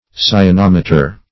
Search Result for " cyanometer" : The Collaborative International Dictionary of English v.0.48: Cyanometer \Cy`a*nom"e*ter\ (s?`?-n?m"?-t?r), n. [Gr. ky`anos a dark blue substance + -meter: cf. F. cyanom[`e]tre.] An instrument for measuring degress of blueness.